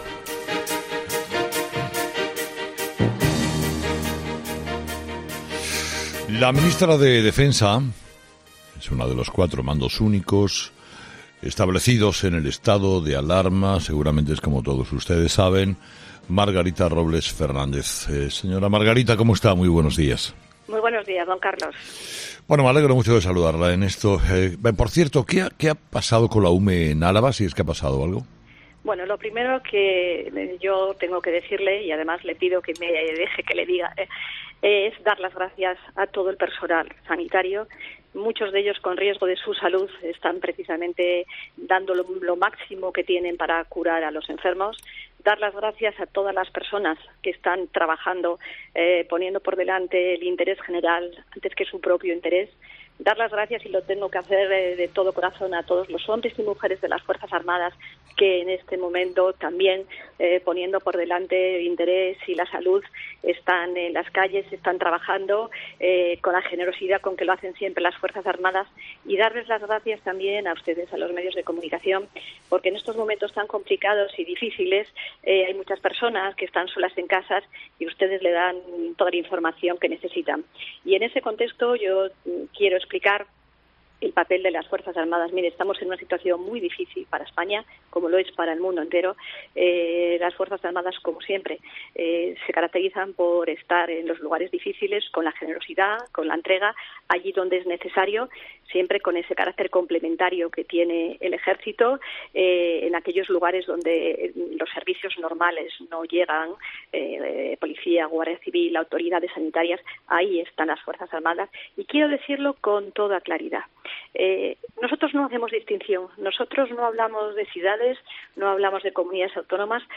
En una entrevista este miércoles en "Herrera en COPE" , la titular de Defensa ha respondido a las críticas por la ausencia de las Fuerzas Armadas Españolas en Cataluña en contraste con otras zonas de España.